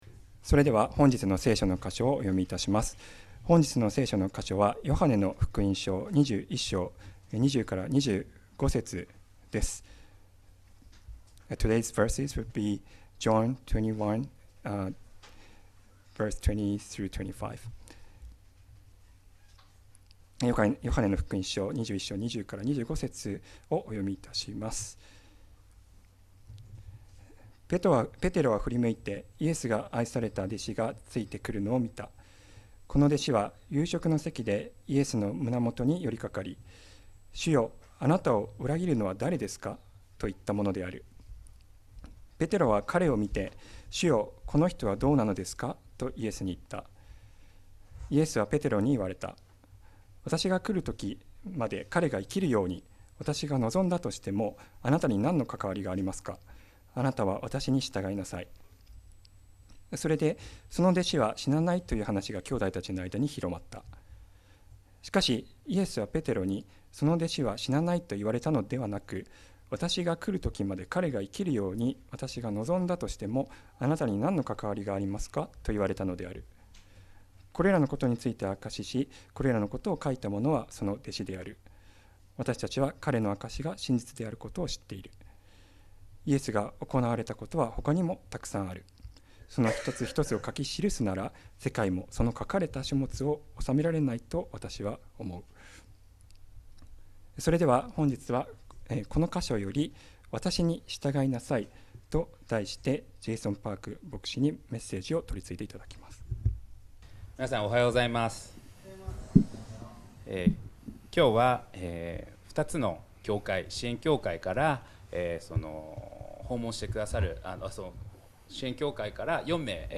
2025年6月22日礼拝 説教 「わたしに従いなさい」